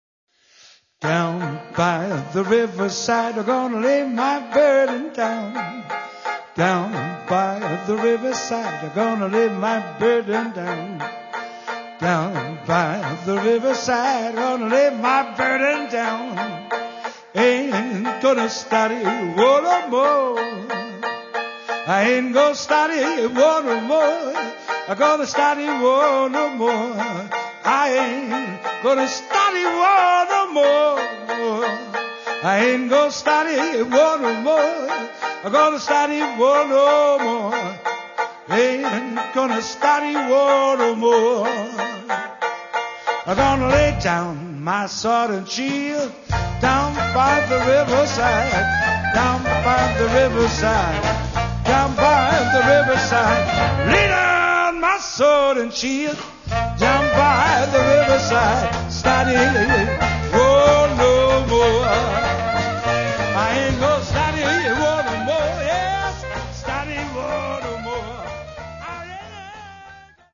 Trompete, Gesang
Posaune
Klarinette
Schlagzeug
Aufgenommen im Klangstudio LEYH, 69207 Sandhausen